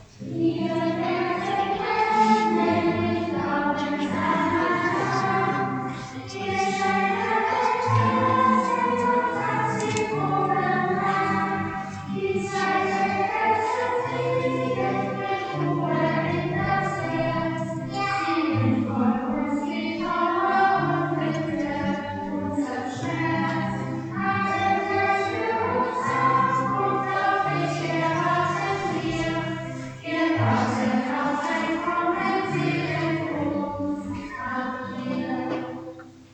Kinderchor der Ev.-Luth.
Audiomitschnitt unseres Gottesdienstes vom 1.Avent 2024